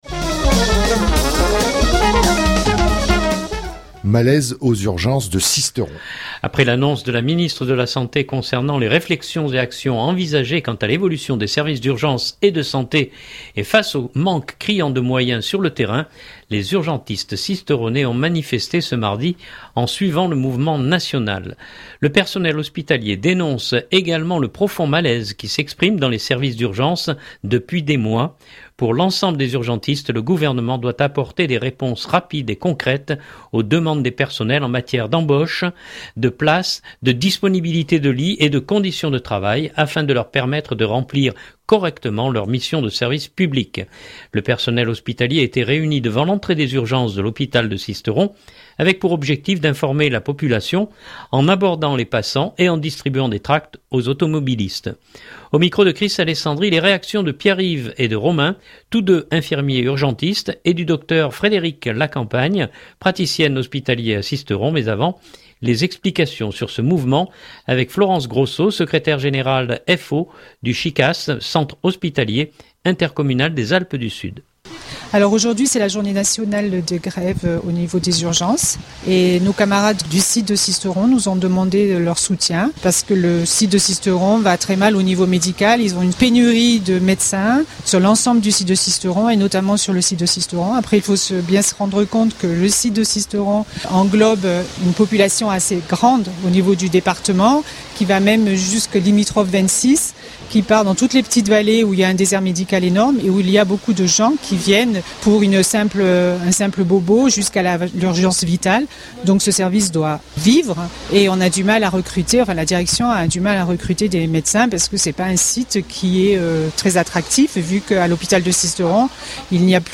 Le personnel hospitalier était réuni devant l’entrée des urgences de l’hôpital de Sisteron, avec pour objectif d’informer la population en abordant les passants et en distribuant des tracts aux automobilistes.